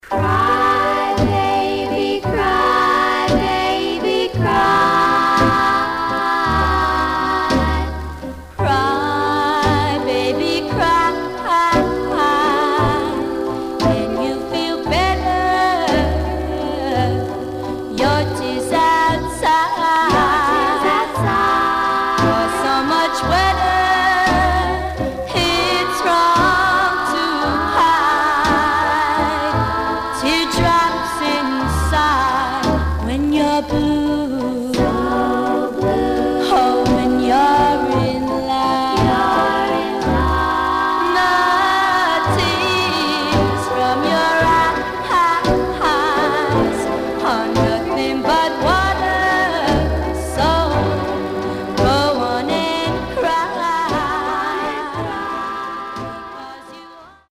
Some surface noise/wear
Mono
White Teen Girl Groups